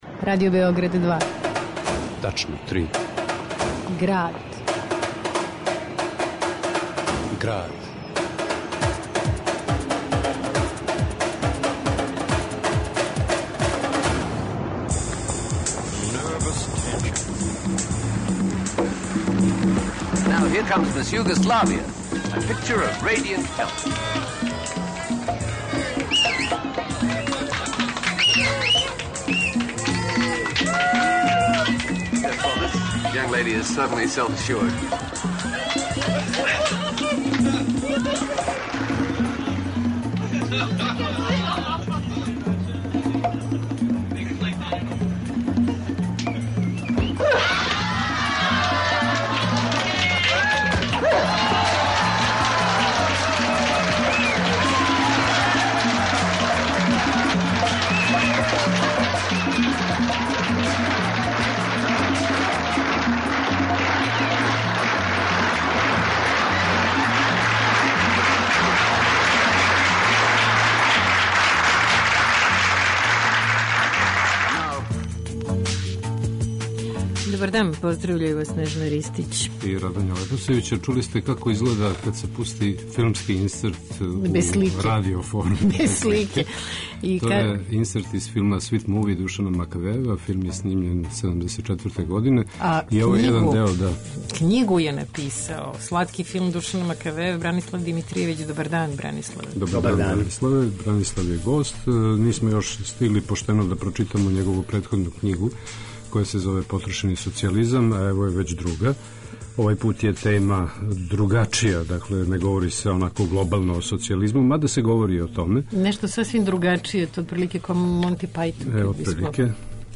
Биће емитовани и одломци из интервјуа са Душаном Макавејевим из 1997.